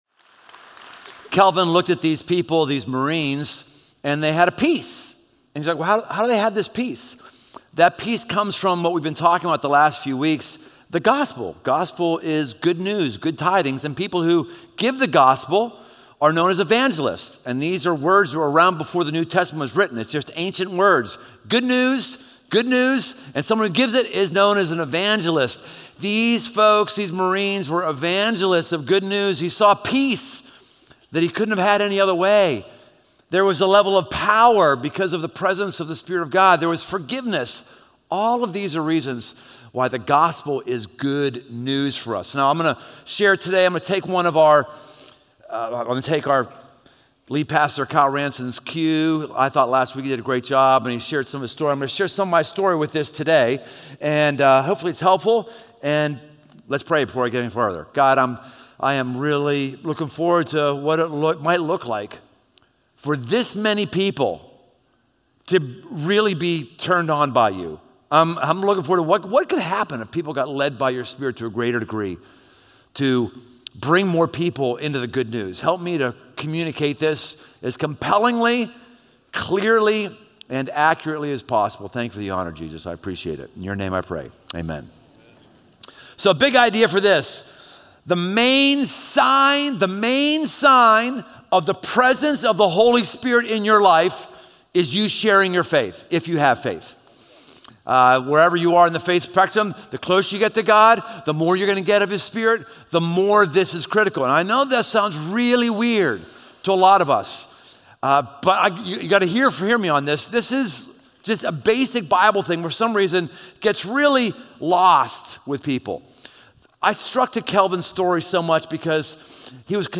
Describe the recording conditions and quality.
Recorded live at Crossroads Church in Cincinnati, Ohio.